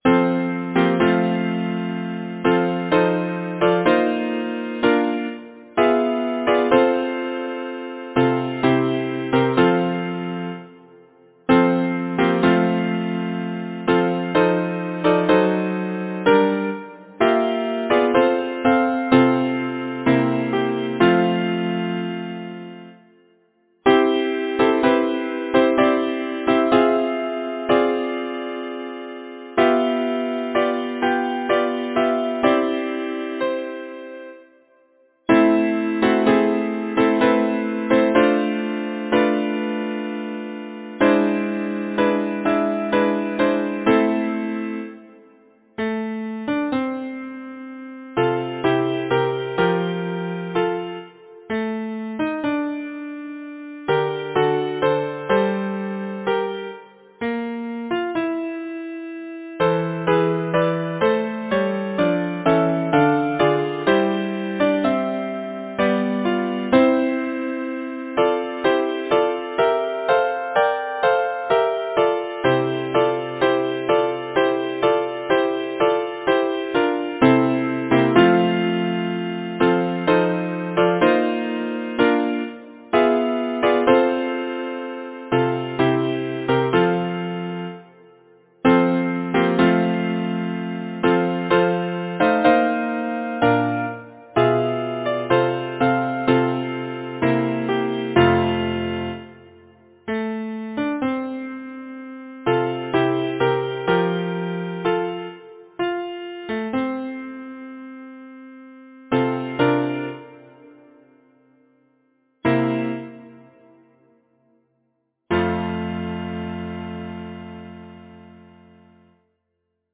Number of voices: 4vv Voicing: SATB Genre: Secular, Partsong
Language: English Instruments: a cappella or Keyboard